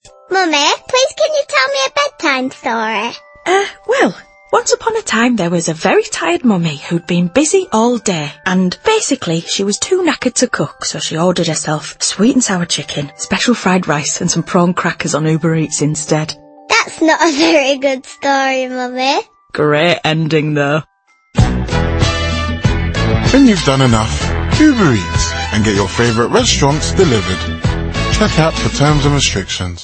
The ad begins with twinkling music and a child’s gentle request: “Mummy, can you tell me a bedtime story?”. When Mum begins with “once upon a time”, the listener readies themself for a tale of a princess in a faraway castle.